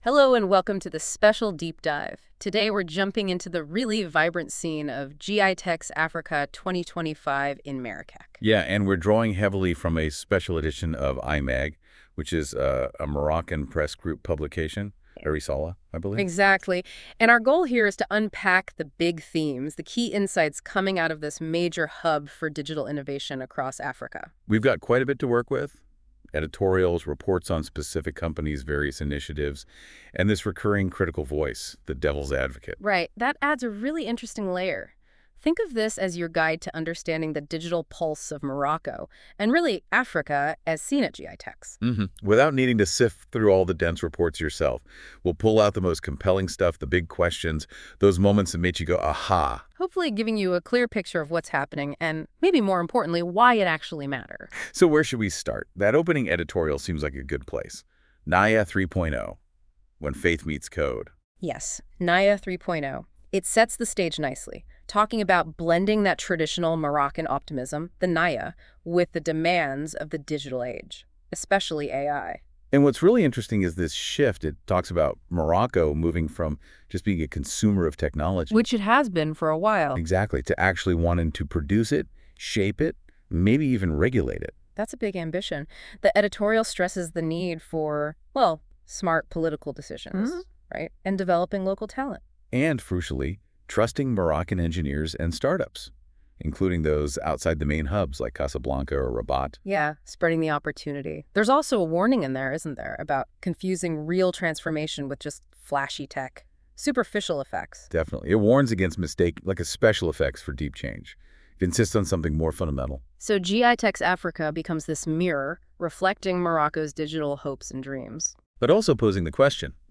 Les chroniqueurs de la Web Radio R212 ont lus attentivement l'hebdomadaire économique de L'ODJ Média et ils en ont débattu dans ce podcast